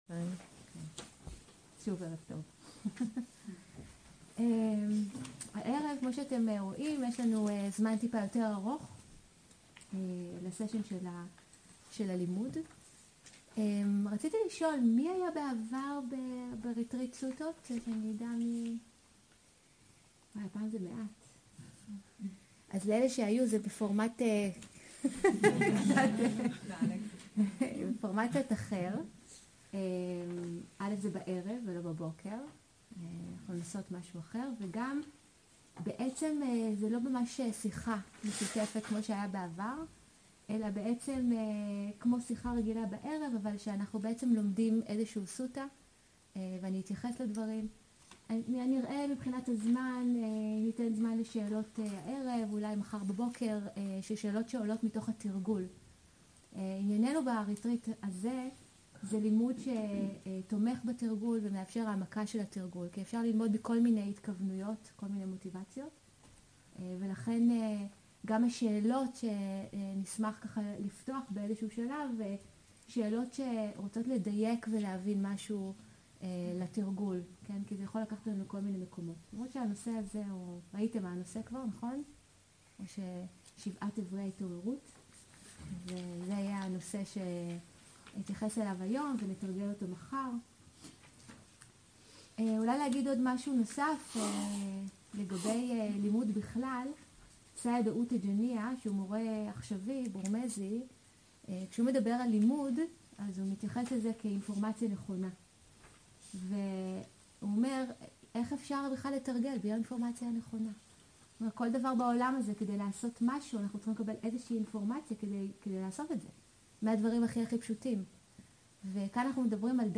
שבעת גורמי ההתעוררות. שיחה וקריאה בסוטה